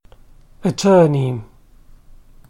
attorney /əˈtɜːni/ or